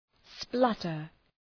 Προφορά
{‘splʌtər}